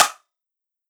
normal-hitclap.wav